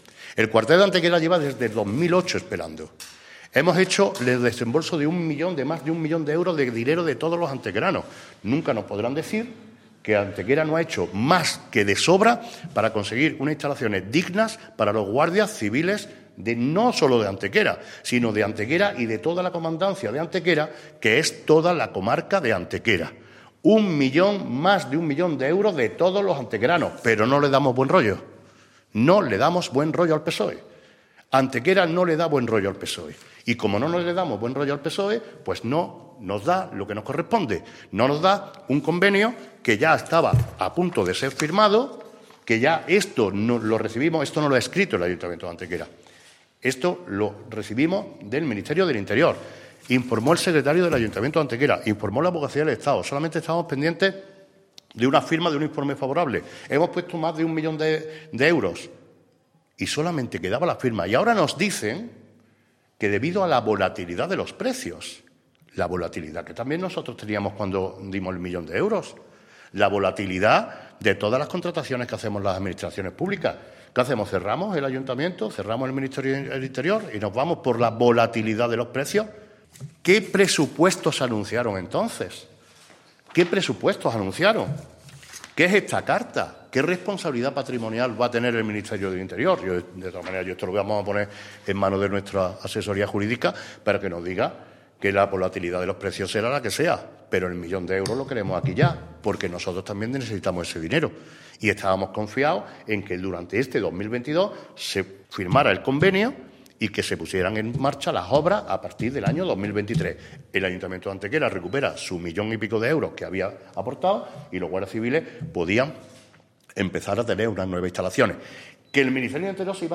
El alcalde de Antequera, Manolo Barón, y el primer teniente de alcalde Juan Rosas han comparecido esta mañana ante los medios de comunicación para informar sobre la última hora del convenio, ya cerrado, que permitiría el desbloqueo del proyecto de construcción del nuevo acuartelamiento de la Guardia Civil en nuestra ciudad, en el aire desde el año 2008.
Cortes de voz